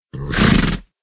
PixelPerfectionCE/assets/minecraft/sounds/mob/horse/idle2.ogg at mc116